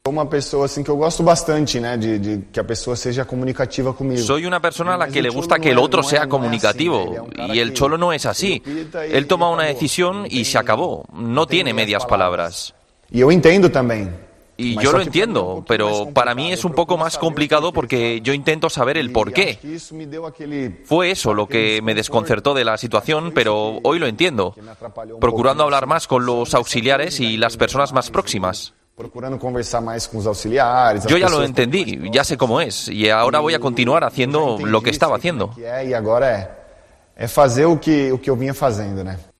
El jugador del Atético habla en una entrevista en TNT sobre su relación con Simeone y confiesa que al principio le costó, pero ahora entiende cómo es el argentino